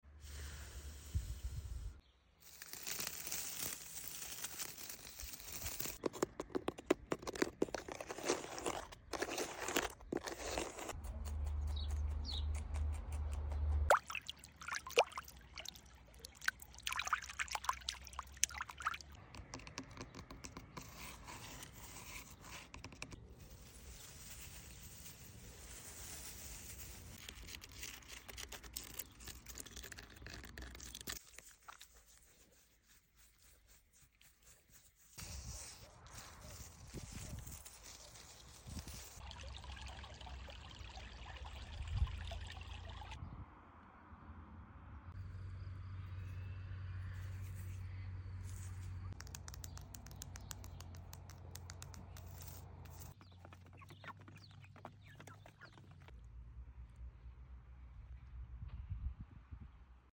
60 seconds of calm with nature and animals. No words, just peaceful ASMR to help you relax.